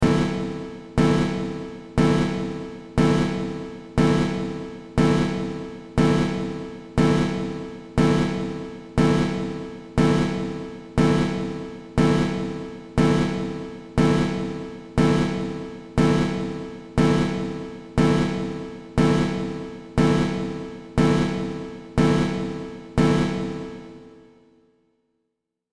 جلوه های صوتی
دانلود صدای هشدار 13 از ساعد نیوز با لینک مستقیم و کیفیت بالا